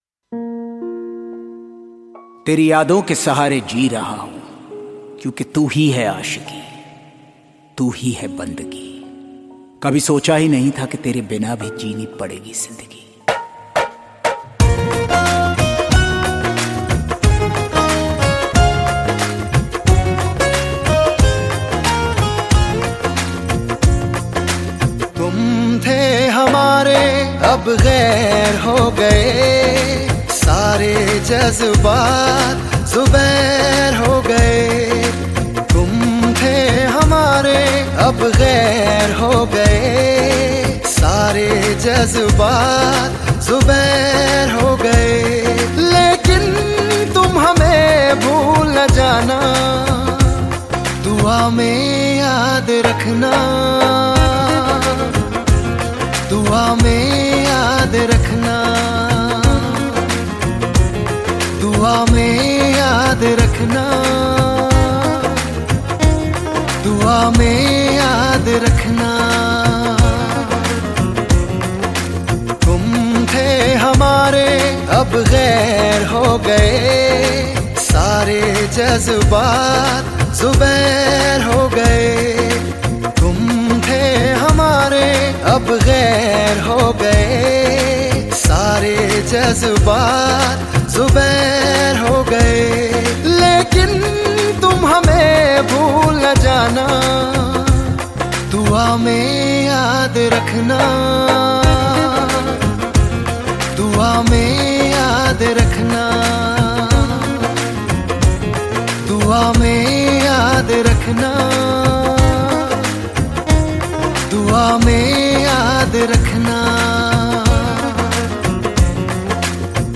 Hindi Pop